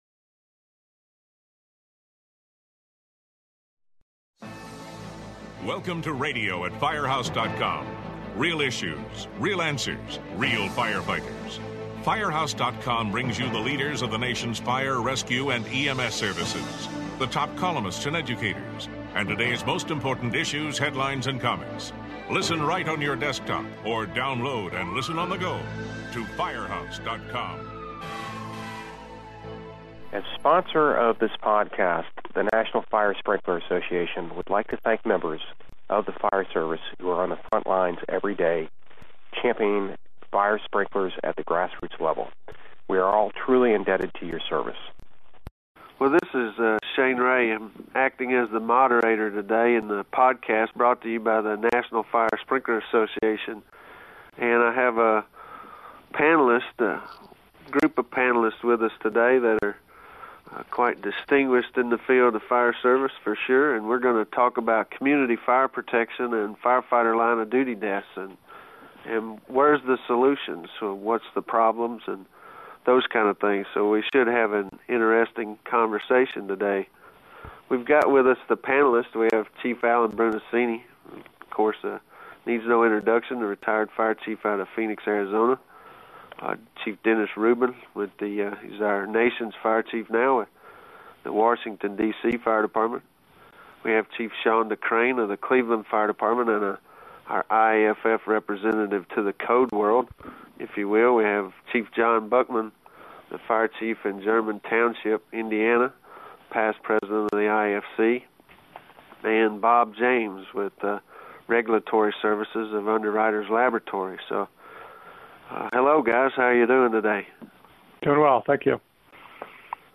The National Fire Sprinkler Association is proud to present a panel discussion featuring Chief Alan Brunacini